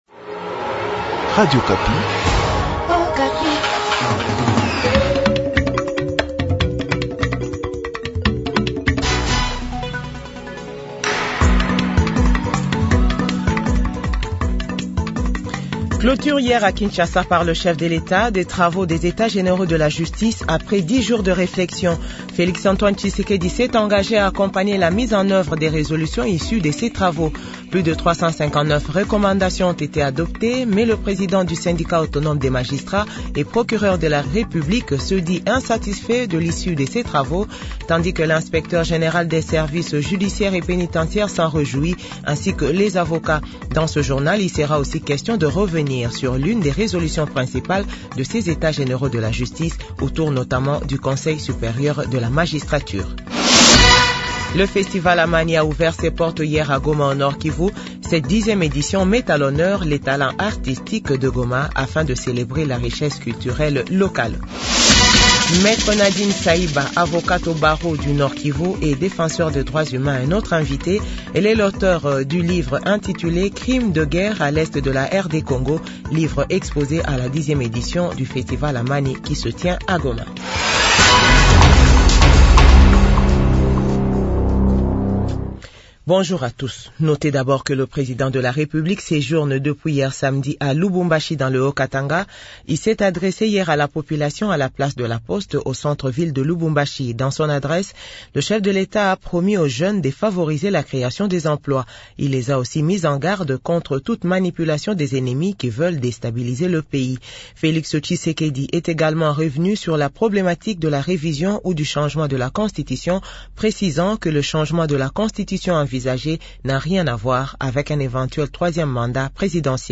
JOURNAL FRANÇAIS 12H00